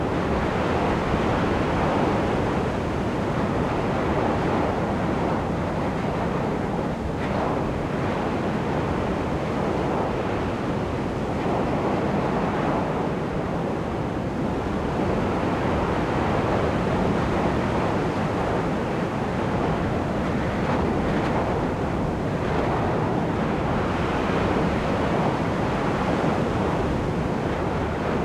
BestWind.ogg